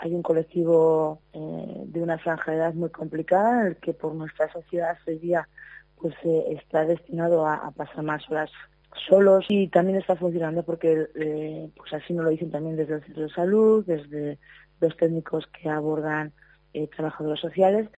La concejala de Servicio Sociales de Tarazona, Lourdes Sánchez, explica el acuerdo con Cruz Roja.